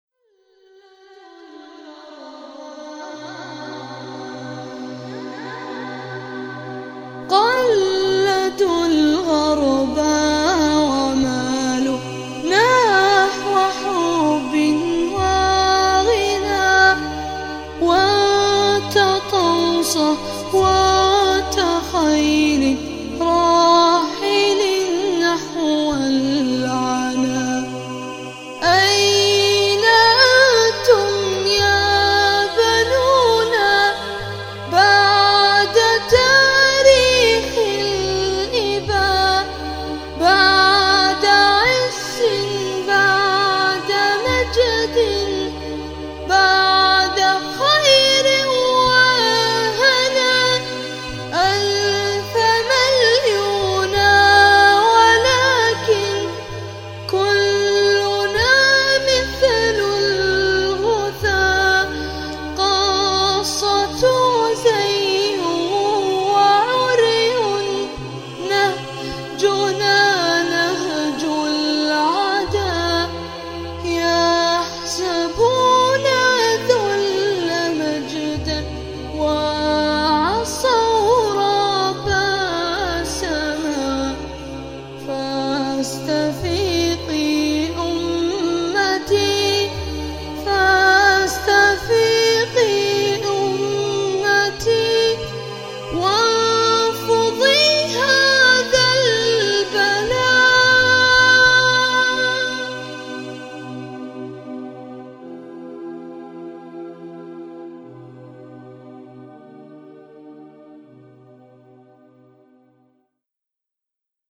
▁ ▂ ▃ ▅ ▆ ▇二【«جديدي 2008 أنشودة ¦
اللحن فونكة
لكن الأوتو تيون مشوّش شوي ..
لحن رااائع
وأداء قوووي